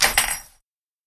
《金属の音》フリー効果音
ガチャリと金属(ビス)を地面に置く効果音。
metal.mp3